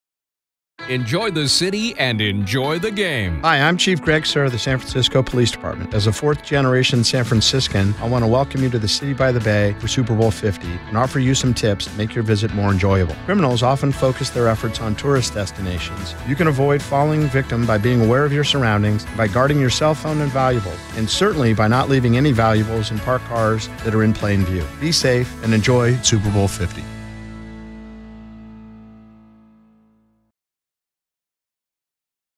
Audio Public Service Announcements (Click to Play, Right Click to Download)
Two 30-second Super Bowl 50 safety radio spots, also featuring Chief Suhr and produced by Cumulus Broadcasting, are attached to this release.